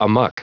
Prononciation du mot amuck en anglais (fichier audio)
Prononciation du mot : amuck